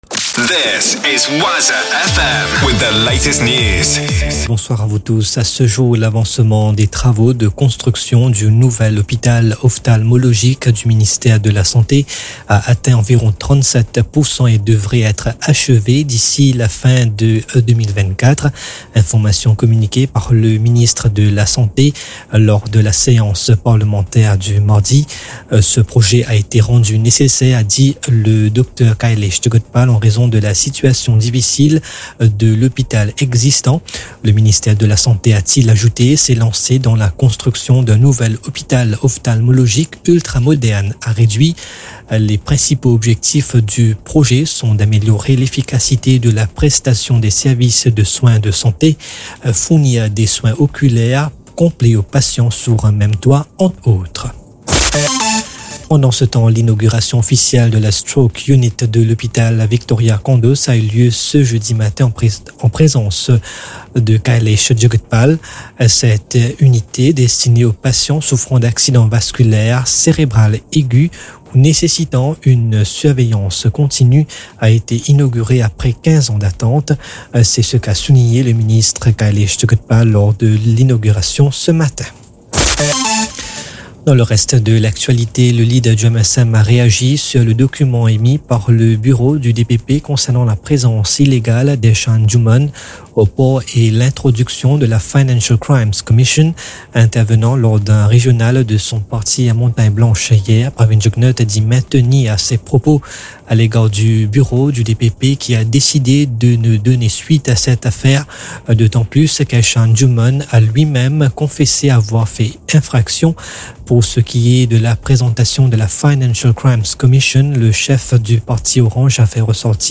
NEWS 20H - 14.12.23